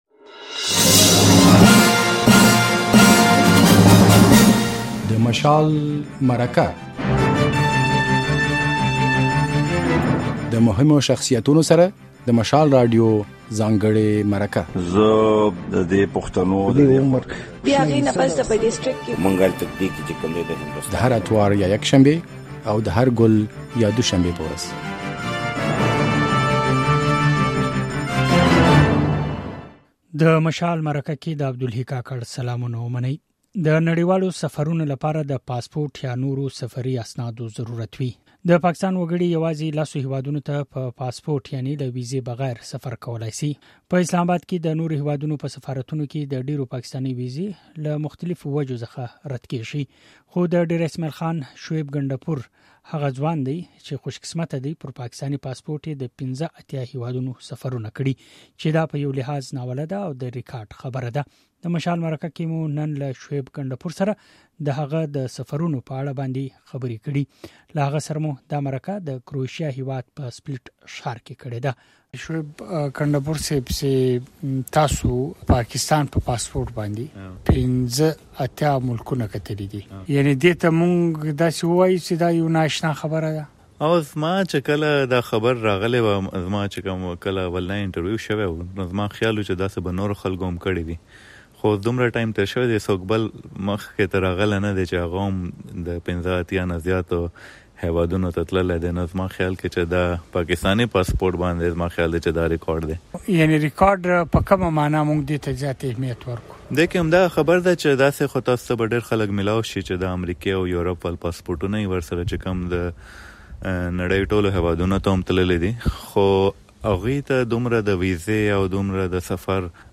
نوموړی د سفرونو کې ترخې او خوږې خاطرې لري چې د مشال مرکه کې یې راسره شريکې کړې دي.